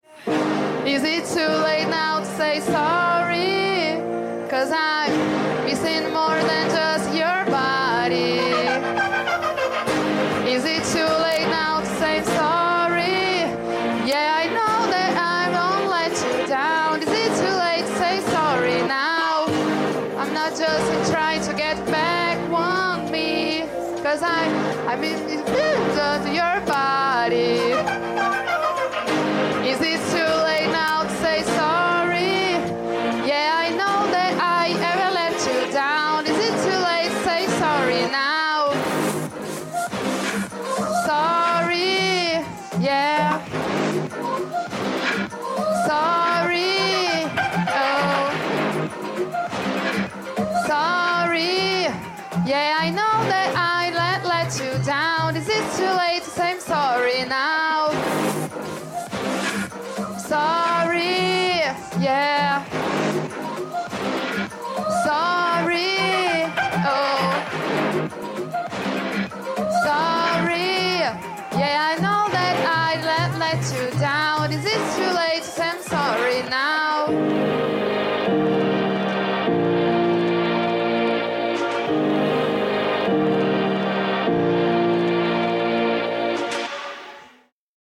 Voz